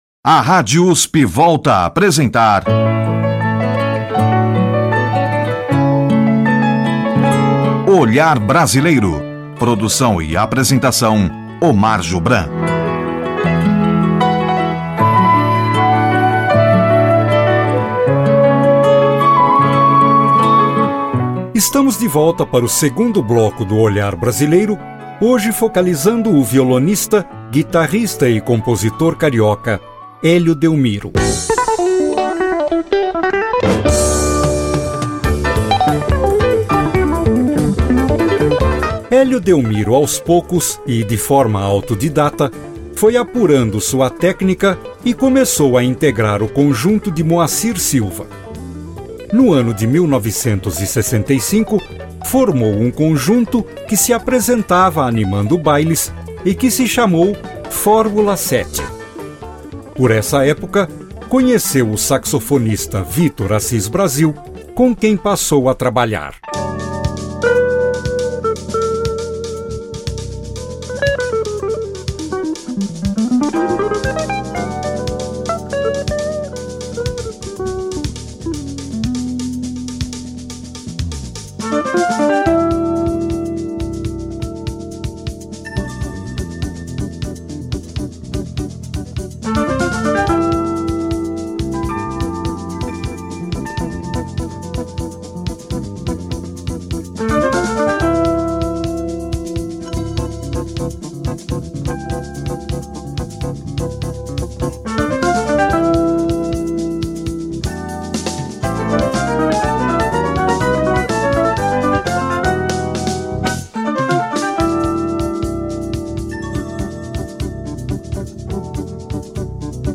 Ouça nos links abaixo o programa Olhar Brasileiro, da Rádio USP (93,7 MHz), transmitido no dia 26 de julho de 2020, que destacou a obra do violonista e guitarrista carioca Hélio Delmiro.